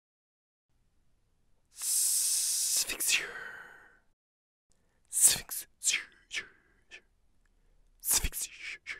how-to-pronounce-xavier.mp3